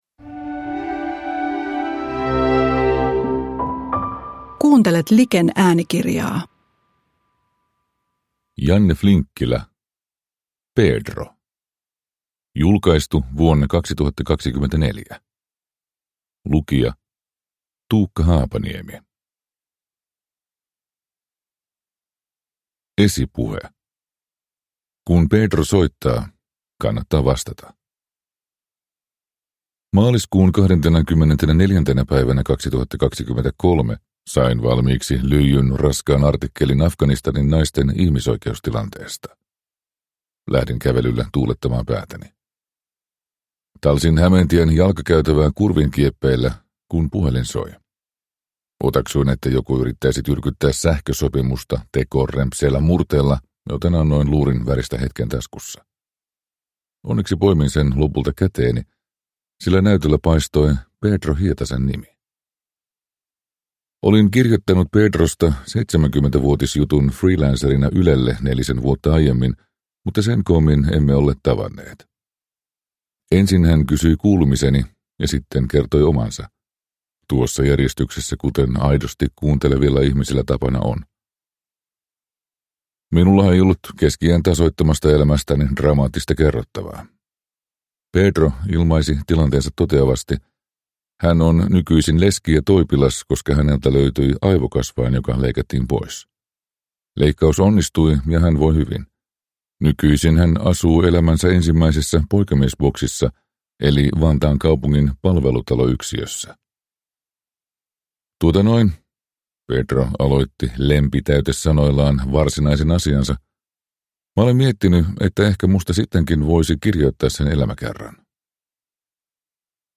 Pedro – Ljudbok